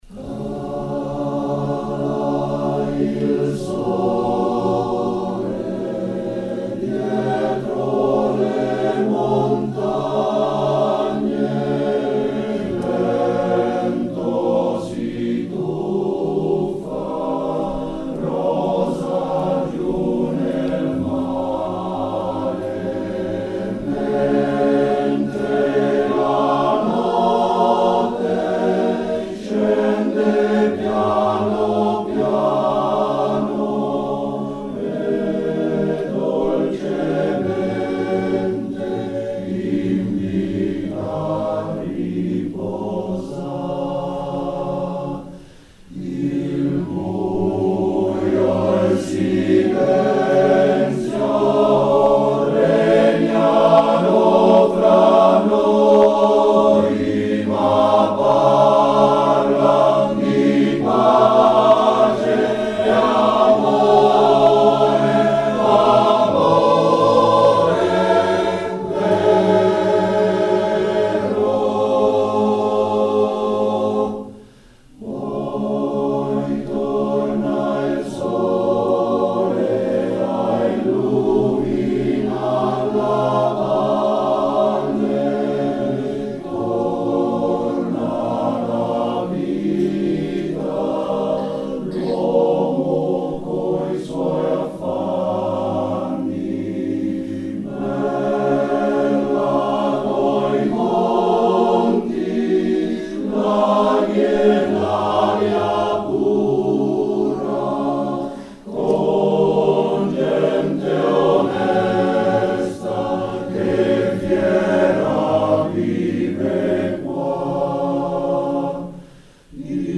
Teatro dei Differenti
Concerto - Canti della Montagna
2 short MP3 files of the concert can be heard here: